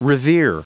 Prononciation du mot revere en anglais (fichier audio)
Prononciation du mot : revere